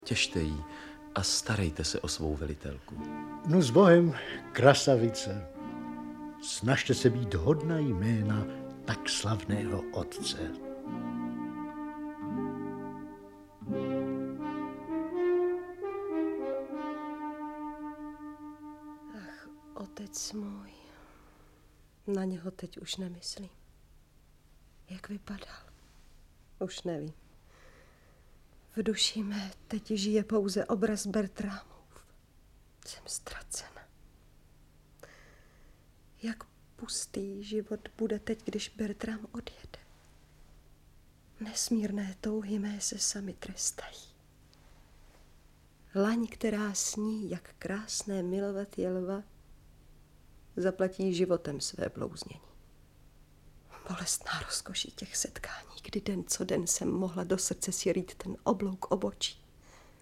Audiobook
Read: Josef Zíma